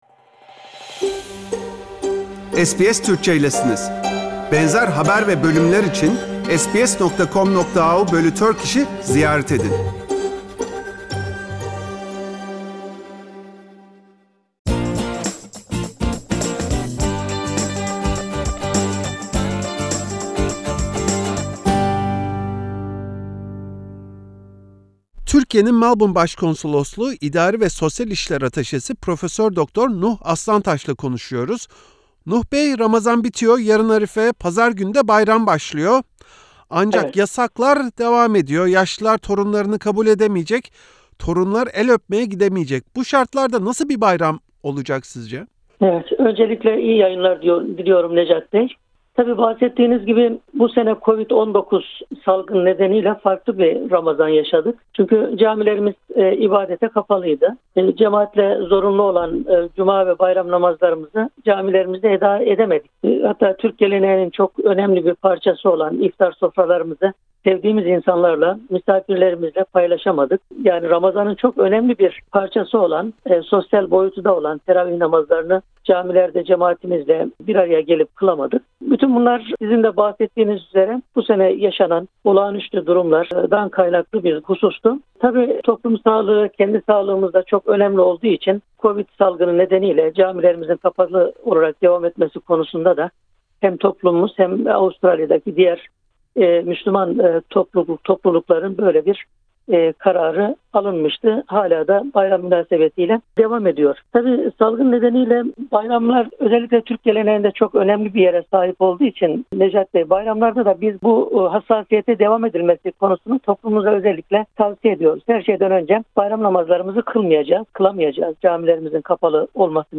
Türkiye’nin Melbourne Başkonsolosluğu İdari ve Sosyal İşler Ataşesi Prof. Dr. Nuh Arslantaş, SBS Türkçe’ye COVID-19 tedbirleri kapsamında Ramazan Bayramı’nın nasıl kutlanacağını anlattı.